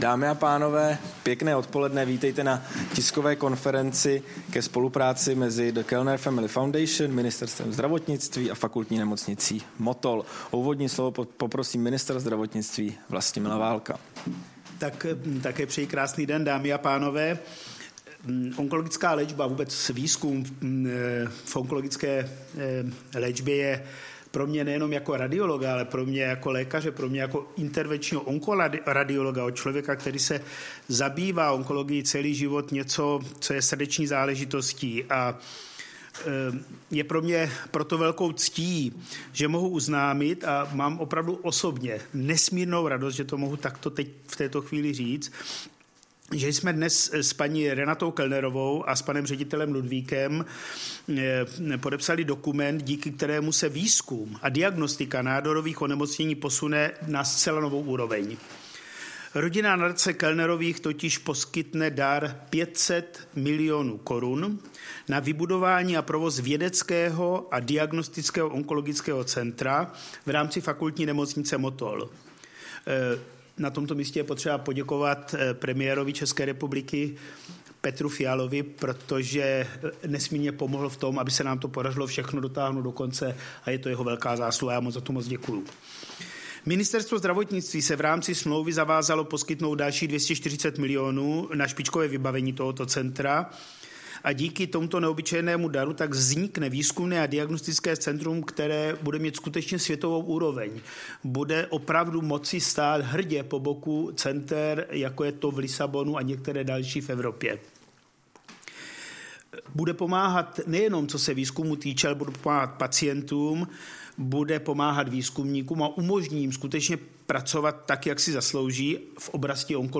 Tisková konference k představení projektu výzkumného onkologického centra ve Fakultní nemocnici v Motole, 5. dubna 2023